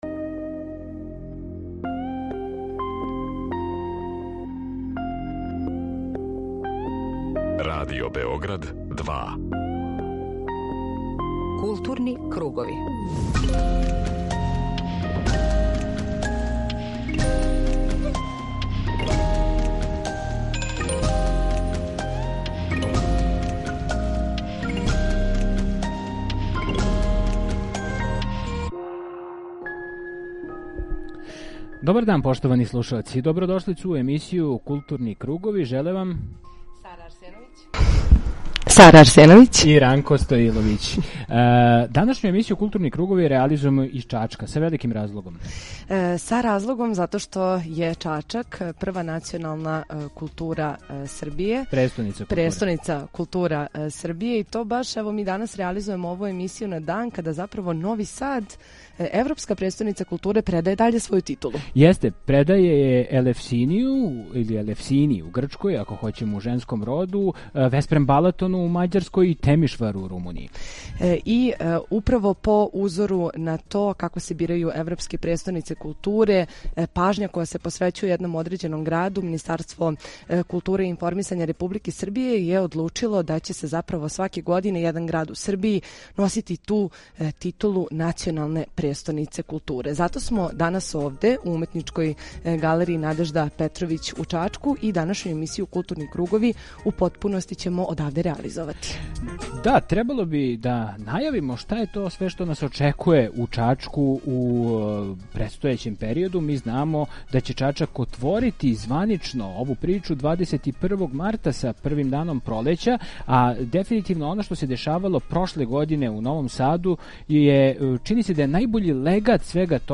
Емисију „Културни кругови" реализујемо из Уметничке галерије „Надежда Петровић" у Чачку, националној престоници културе за 2023. годину.